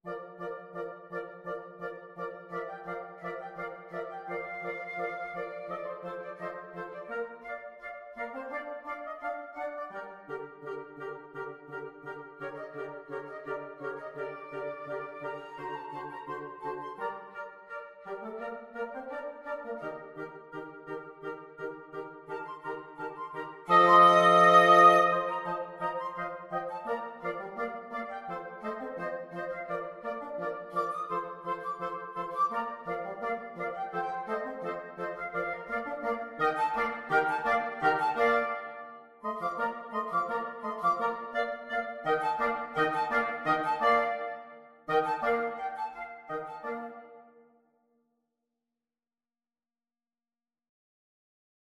Free Sheet music for Wind Quartet
FluteOboeClarinetBassoon
Bb major (Sounding Pitch) (View more Bb major Music for Wind Quartet )
= 85 Allegro scherzando (View more music marked Allegro)
4/4 (View more 4/4 Music)
Wind Quartet  (View more Easy Wind Quartet Music)
Classical (View more Classical Wind Quartet Music)